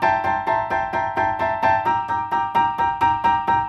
Index of /musicradar/gangster-sting-samples/130bpm Loops
GS_Piano_130-E1.wav